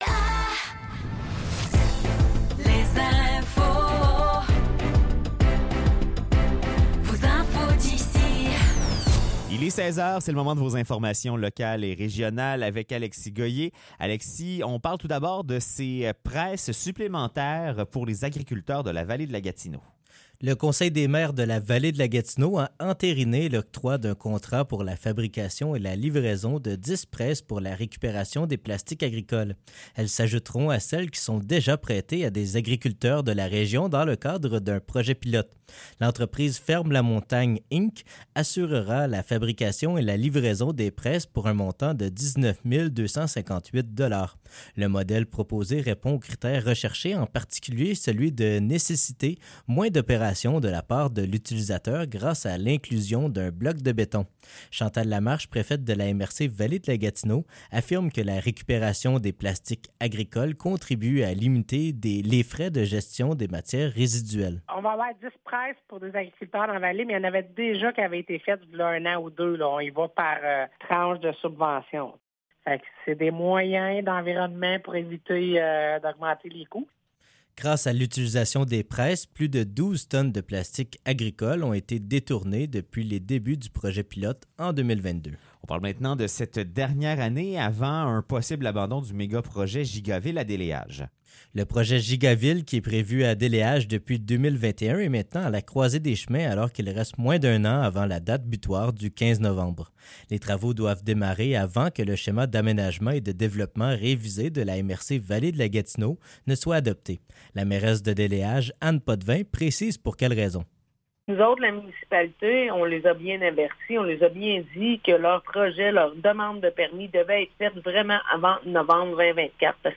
Nouvelles locales - 14 décembre 2023 - 16 h